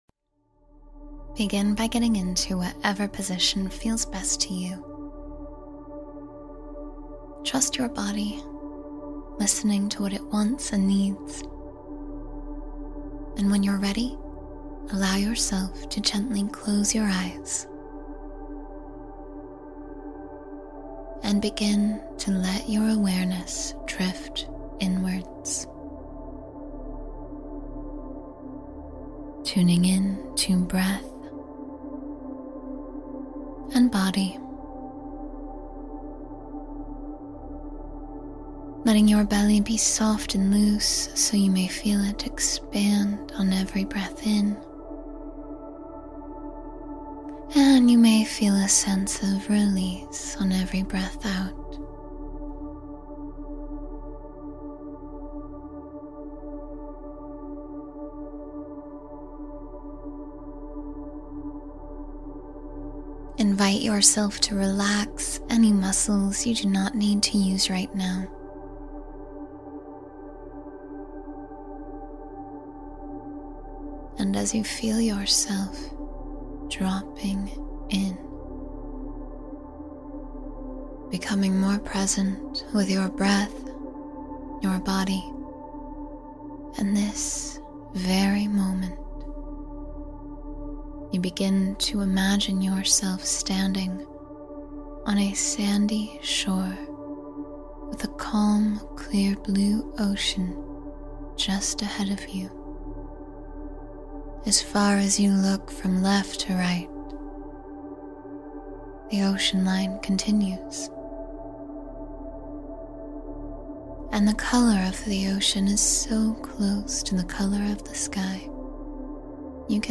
Heal and Balance Chakras in 10 Minutes — Quick Meditation for Energy Alignment